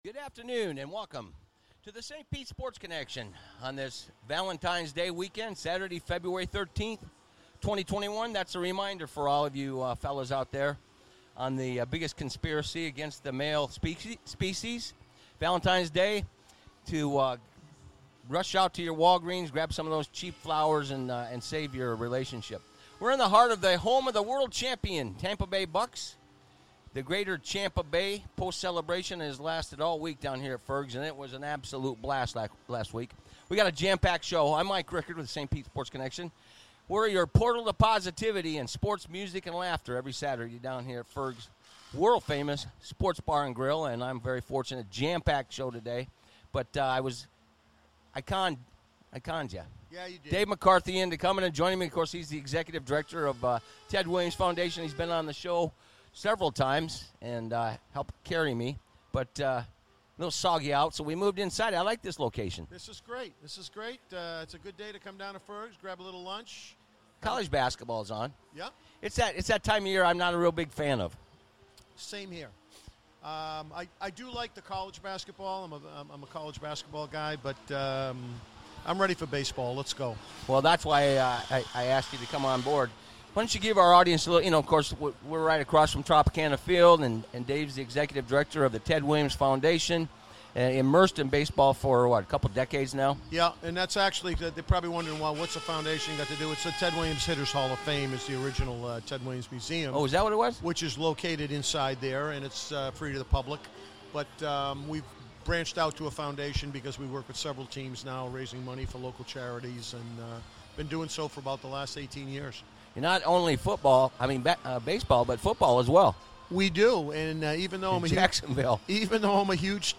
St. Pete Sports Connection 2-13-21 Live from Ferg's Sports Bar and Grill; Airs live 12:15pm-2pm. RadioStPete Online